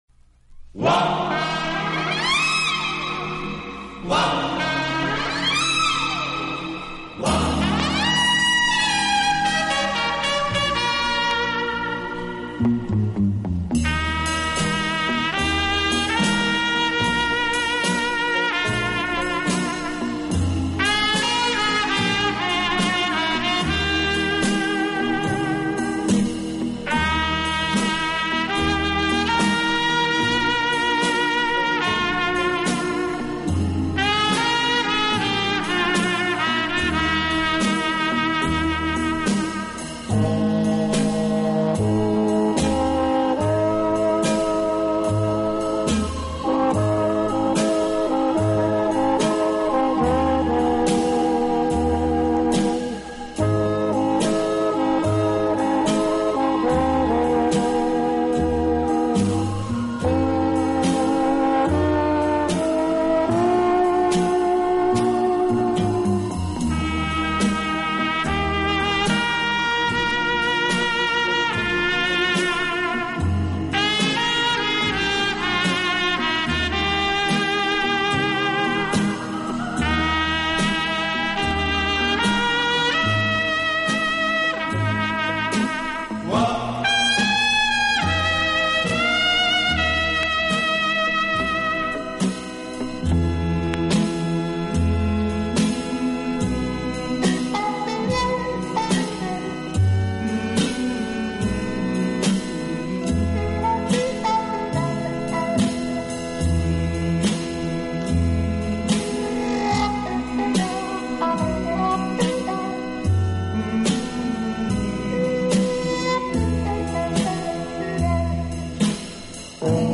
小号的音色，让他演奏主旋律，而由弦乐器予以衬托铺垫，音乐风格迷人柔情，声情并
温情、柔软、浪漫是他的特色，也是他与德国众艺术家不同的地方。